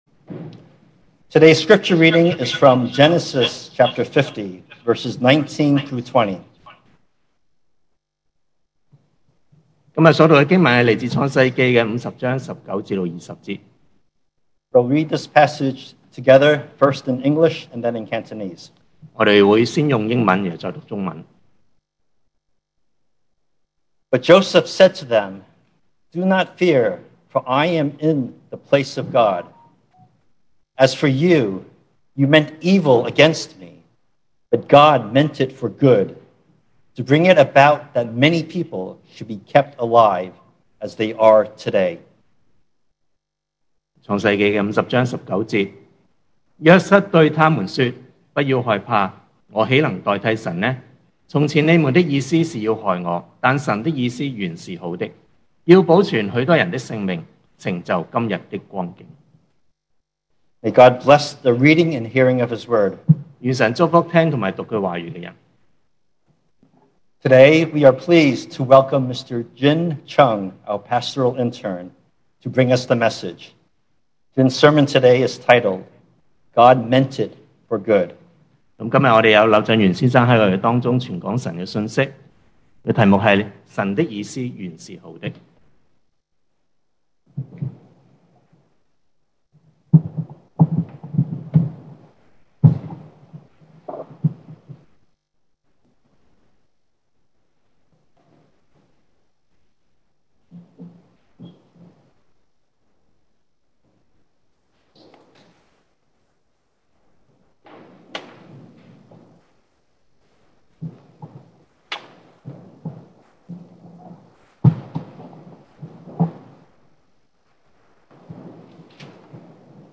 2021 sermon audios
Service Type: Sunday Morning